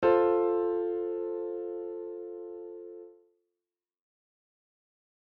F chord.mp3